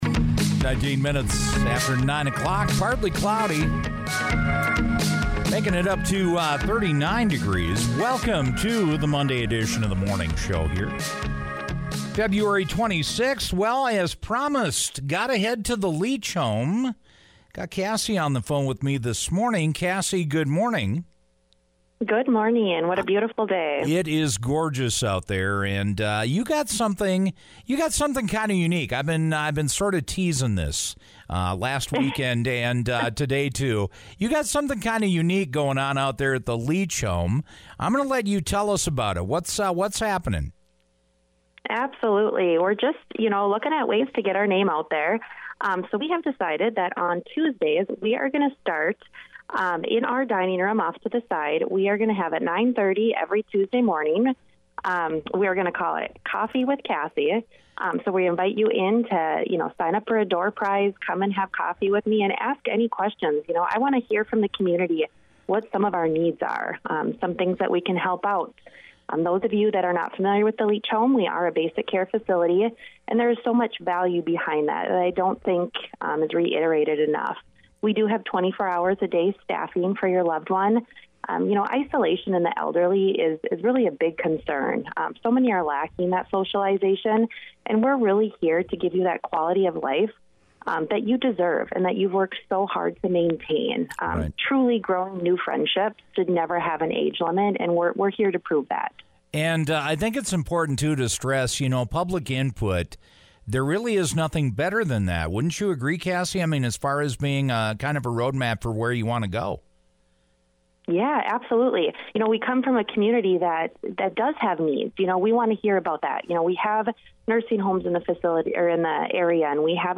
Listen to our podcast conversation below.